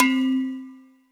AFFRBELLC3-R.wav